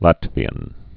(lătvē-ən)